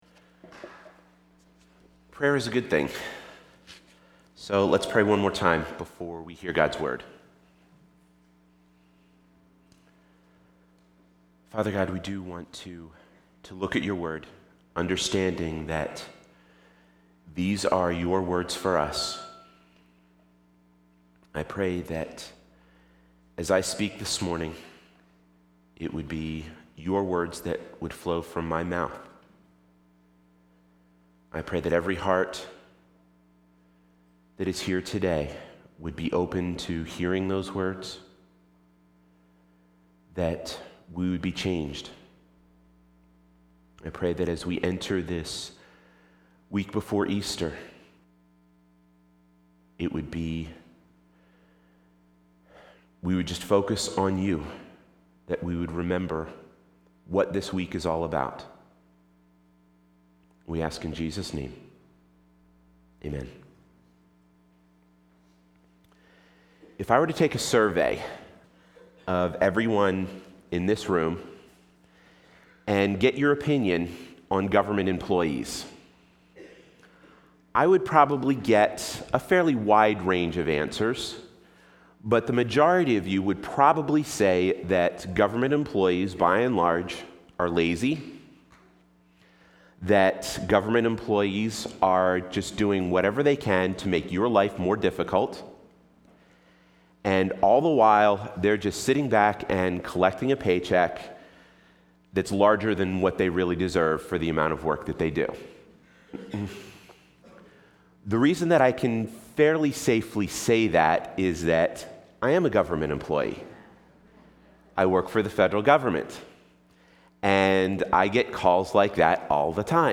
Palm Sunday 2010